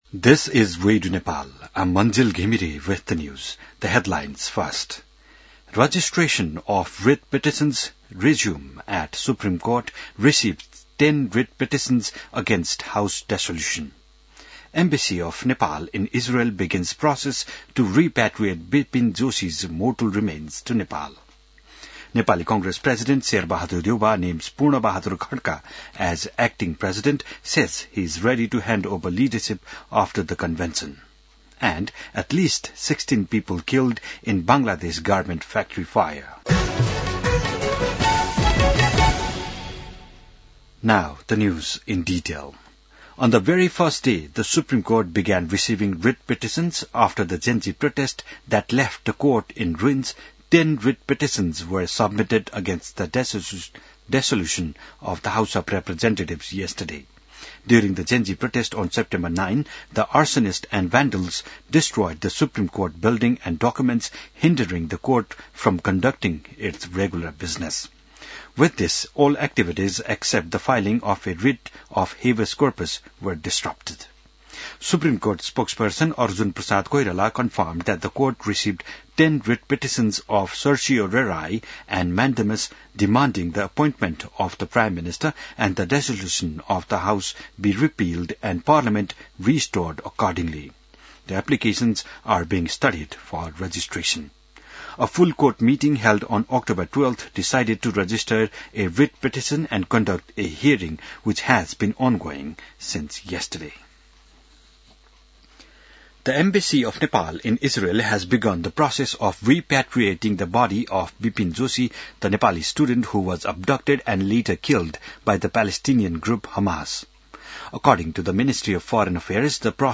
बिहान ८ बजेको अङ्ग्रेजी समाचार : २९ असोज , २०८२